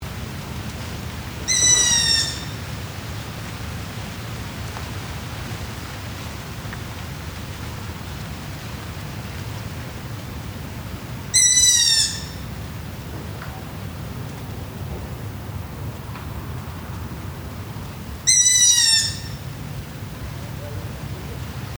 Asio otus